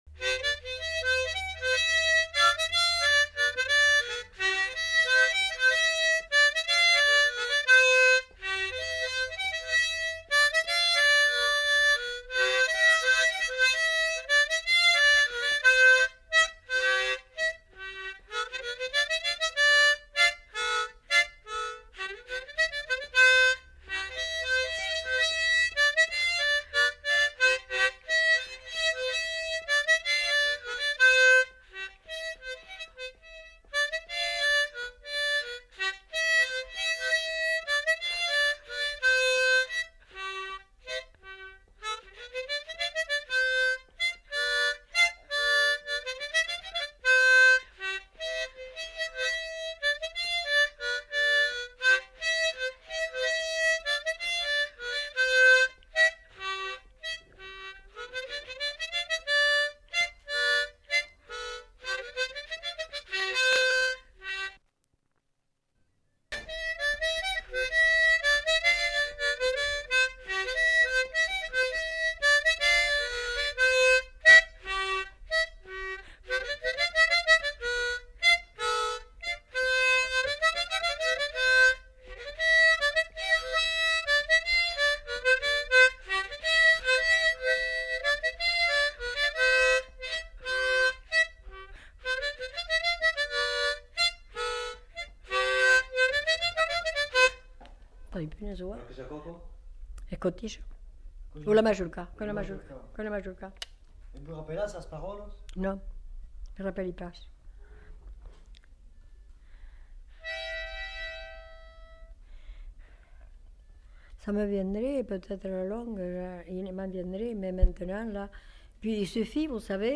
Mazurka
Aire culturelle : Haut-Agenais
Lieu : Cancon
Genre : morceau instrumental
Instrument de musique : harmonica
Danse : mazurka
Notes consultables : Le morceau est joué deux fois.